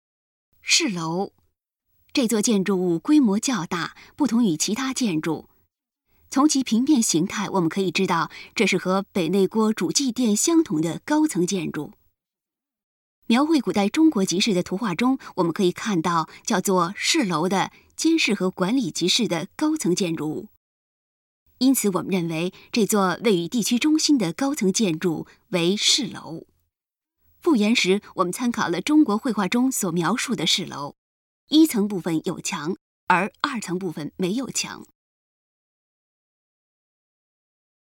语音导览 前一页 下一页 返回手机导游首页 (C)YOSHINOGARI HISTORICAL PARK